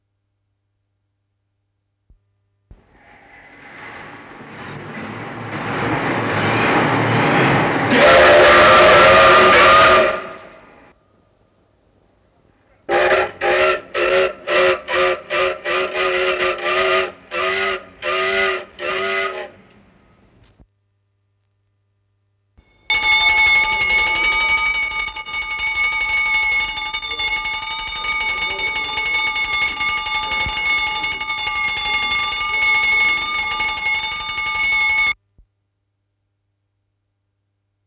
с профессиональной магнитной ленты
Название передачиШумы
РедакцияШумовая
Содержание1. Сигналы тревоги (звонок)
2. Сирены на пожарных машинах
ВариантМоно